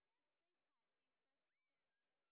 sp16_exhibition_snr0.wav